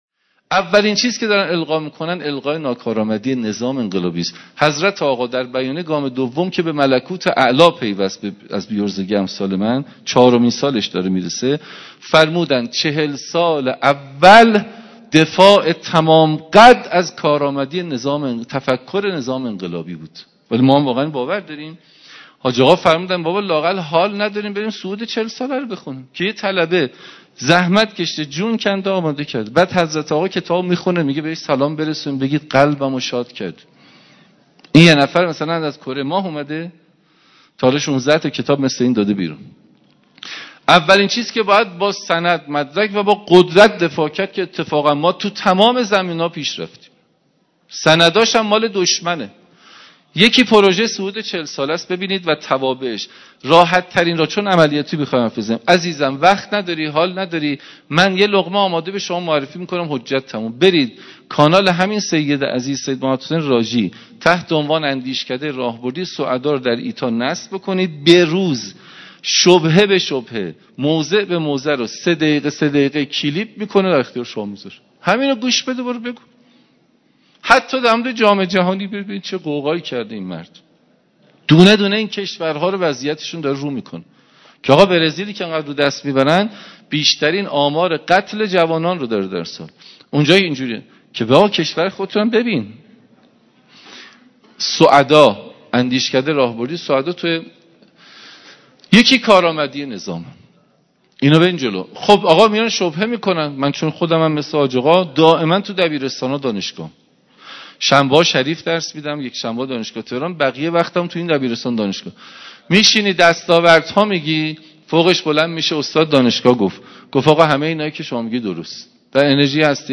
کارآمدی نظام/ فایل با کیفیت معمولی